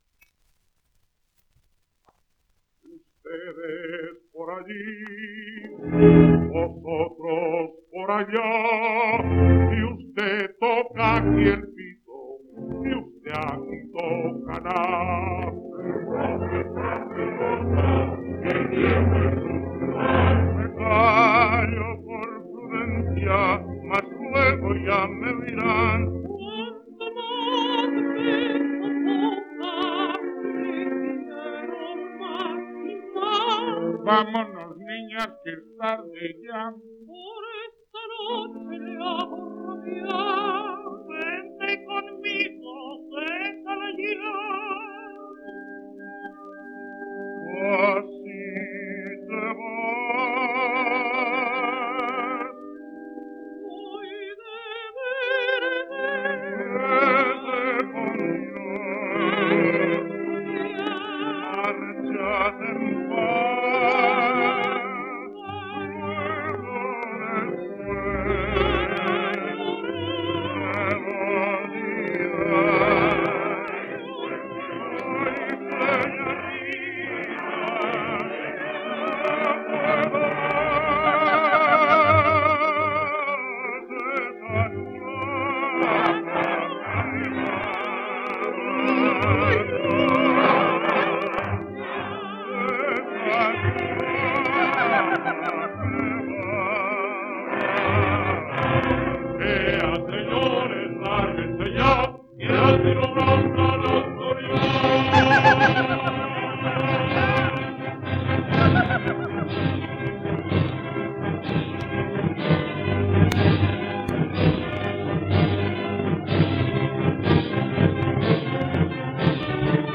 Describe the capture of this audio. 3 discos : 78 rpm, mono ; 25 cm.